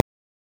click_005.ogg